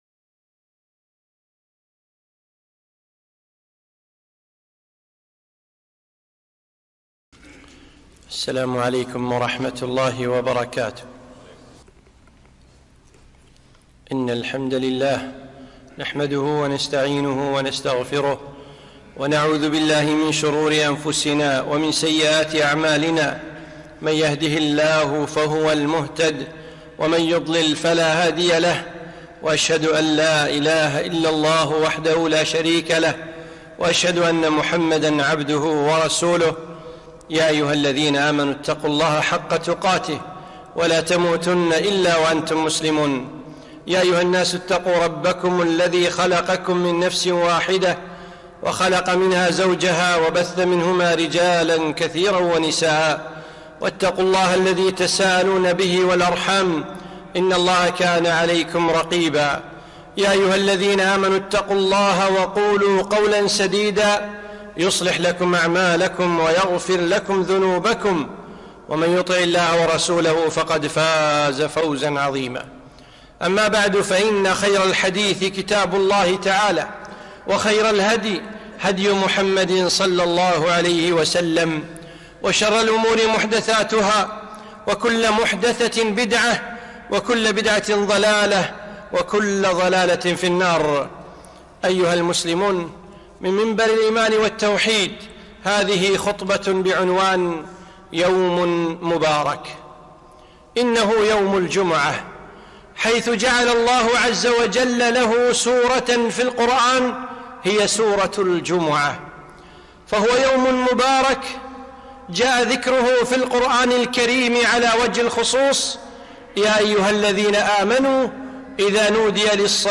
خطبة - يوم مبارك